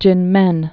(jĭnmĕn)